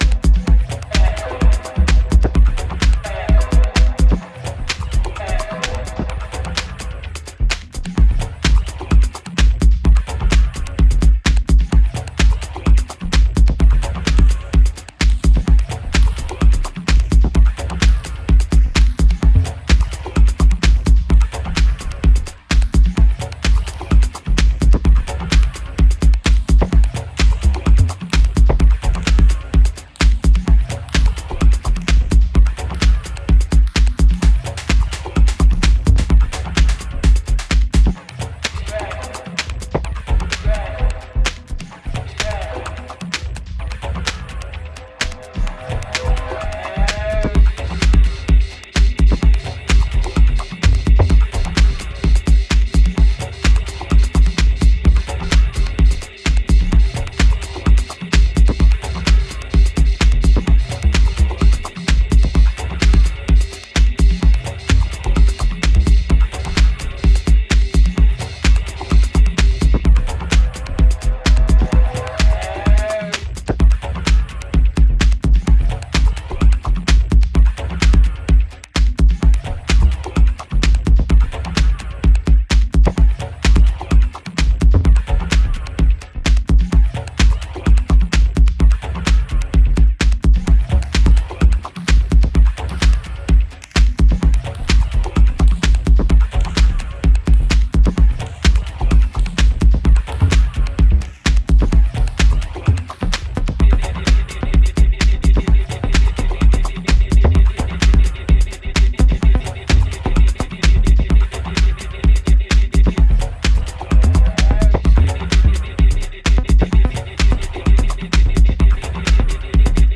Built for late-night floors and intimate settings alike
timeless grooves